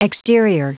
Prononciation du mot exterior en anglais (fichier audio)
Prononciation du mot : exterior